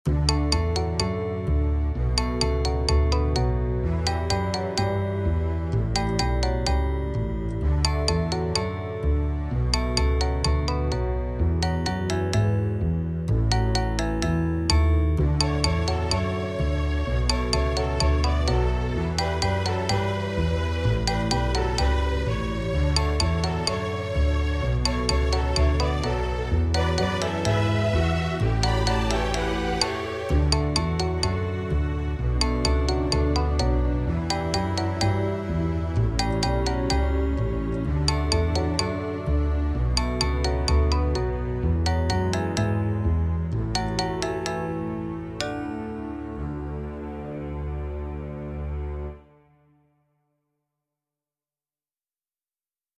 Scene Change, Theater Music